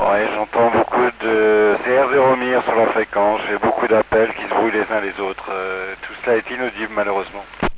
QSO PHONIE avec MIR